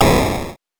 8 bits Elements / explosion
explosion_15.wav